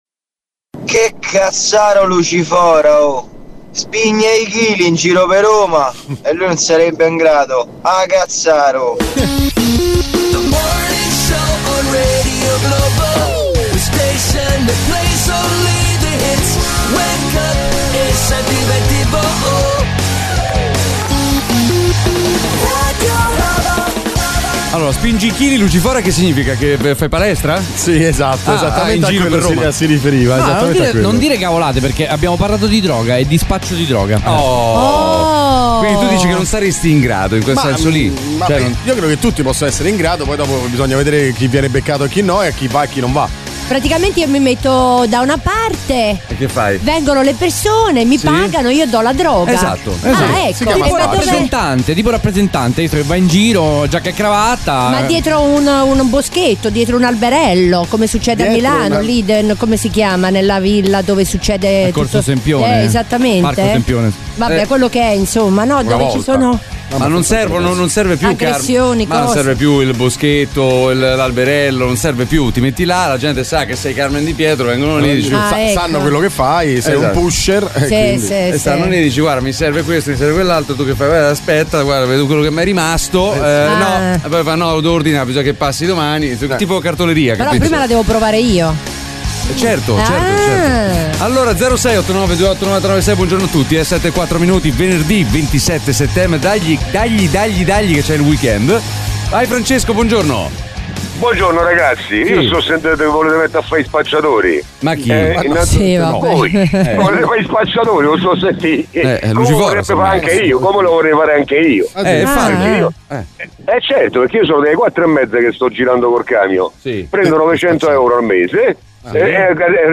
Umorismo, attualità, aggiornamenti sul traffico in tempo reale e l'immancabile contributo degli ascoltatori di Radio Globo, protagonisti con telefonate in diretta e note vocali da Whatsapp.